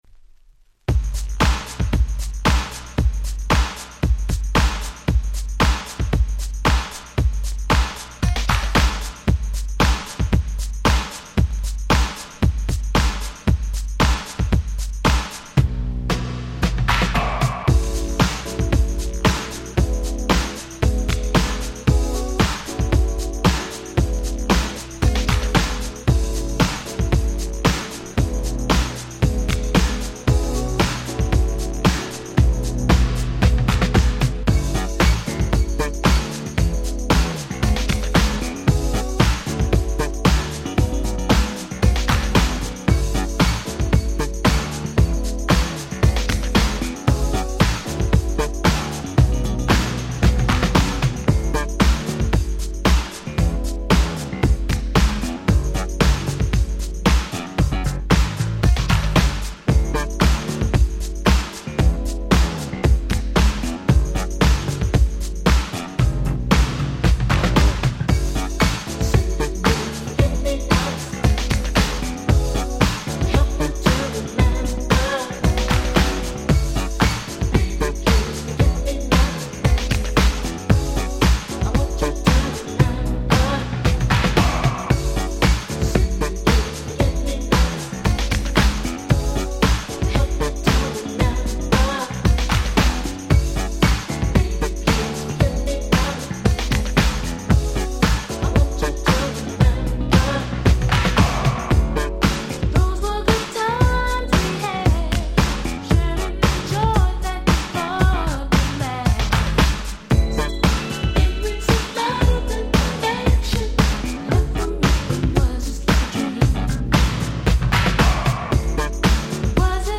ウィッキド ウィッキード ミックス物 90's R&B Hip Hop 勝手にリミックス 勝手にRemix